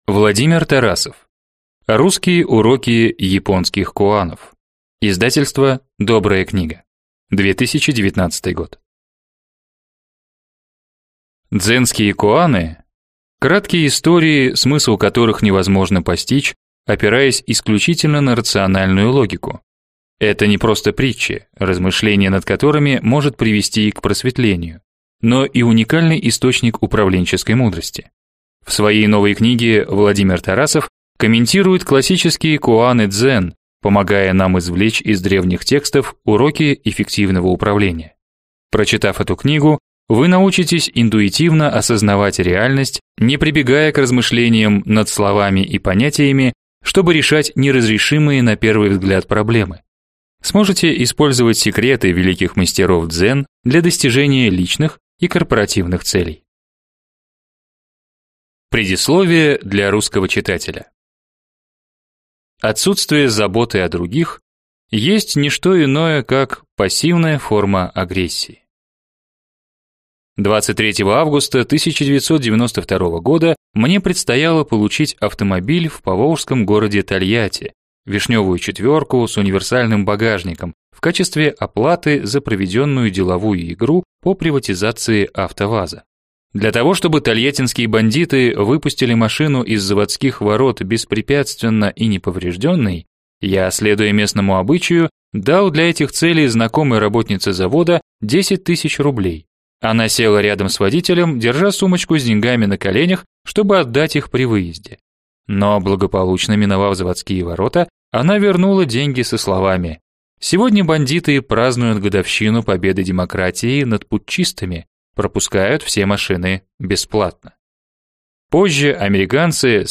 Аудиокнига Русские уроки японских коанов | Библиотека аудиокниг